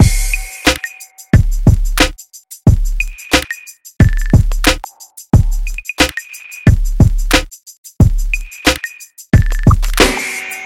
黑手党大鼓
Tag: 90 bpm Hip Hop Loops Drum Loops 1.79 MB wav Key : Unknown